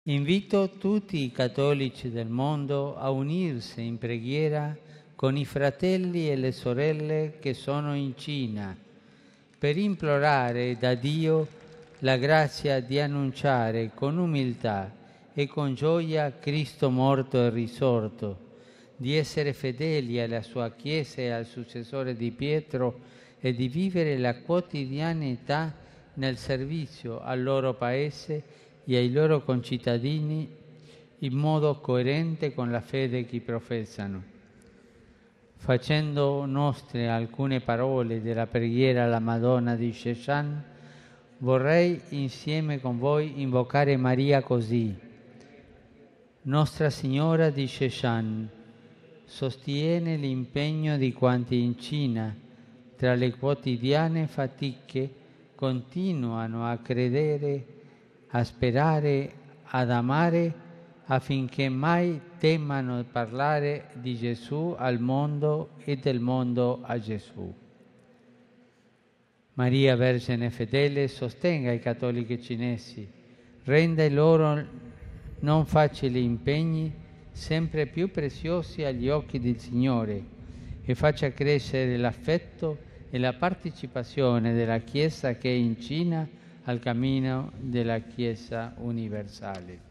Il Papa, al termine dell’udienza generale, l'ha ricordata.